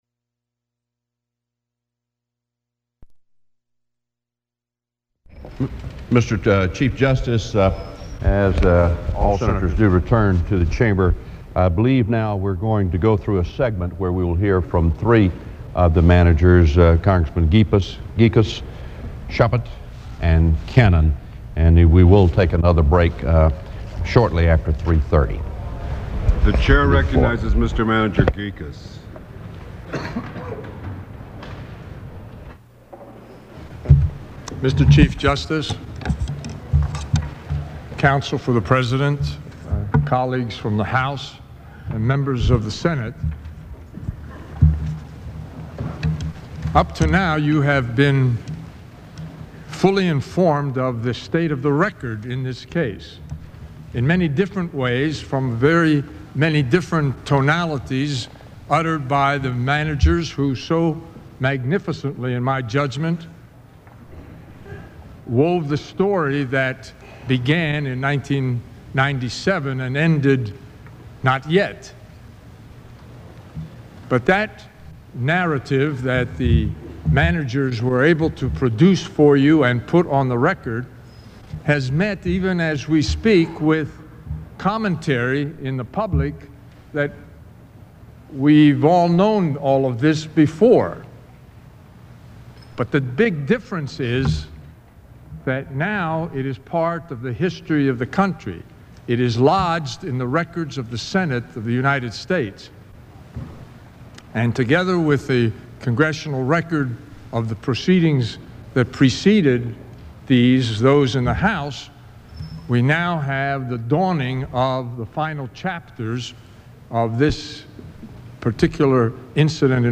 George Gekas (R-PA) testifies in the impeachment of President Clinton. Other speakers include Steve Chabot (R-OH) and Senate Majority Leader Trent Lott (R-MS).
Broadcast on NPR, January 15, 1999.